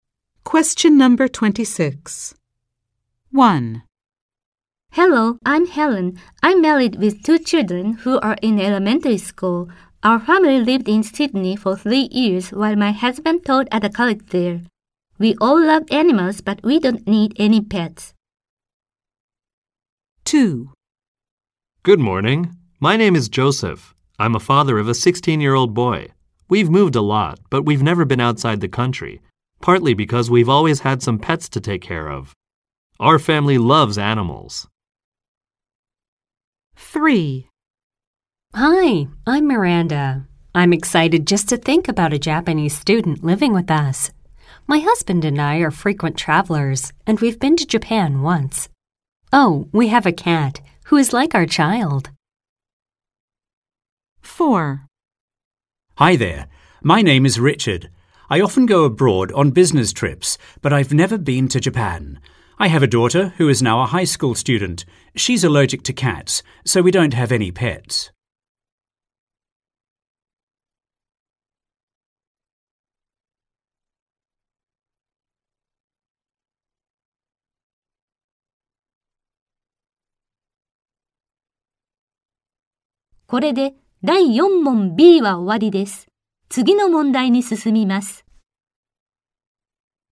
〇アメリカ英語に加えて、イギリス英語、アジア英語の話者の音声も収録しています。
〇早口で実録音したハイスピード音声をダウンロードにて提供。
ノーマル・スピード音声   ハイ・スピード音声